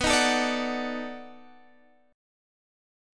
B7#9 Chord
Listen to B7#9 strummed